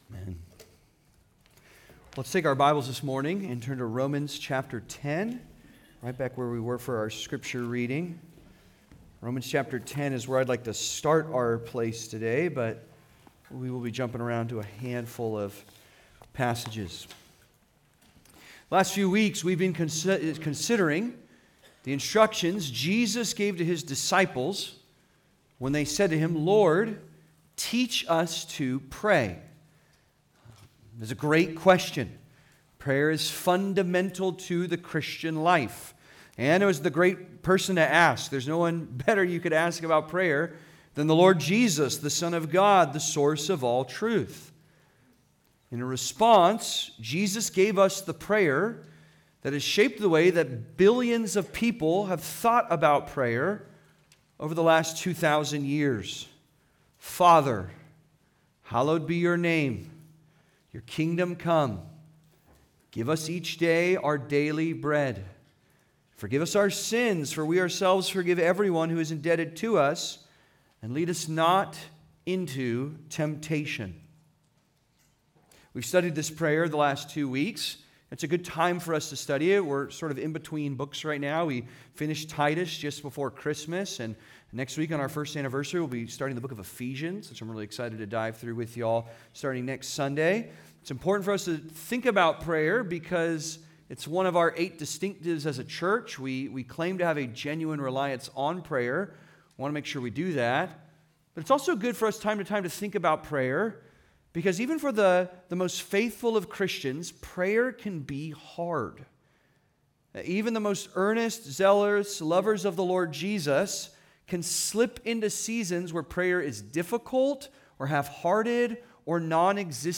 Praying Together: Pleading for the Perishing (Sermon) - Compass Bible Church Long Beach